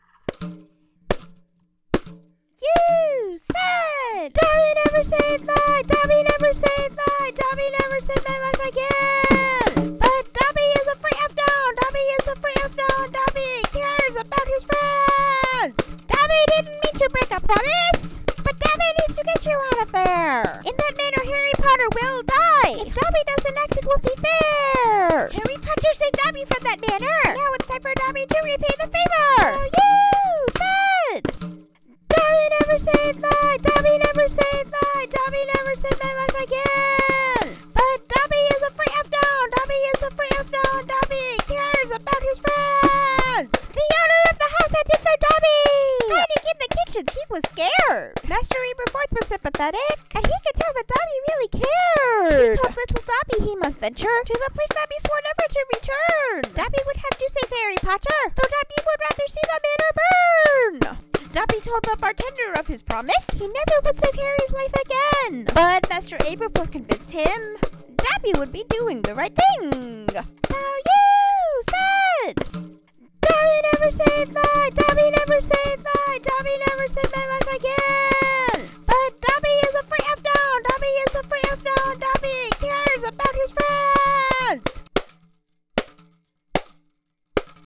a sneak peek of an in-progress song
I worked hard on the Dobby voice, listening to the song Dobby’s Draw My Life (Avbyte) and the Dobby scene from the movies a lot.